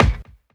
kick03.wav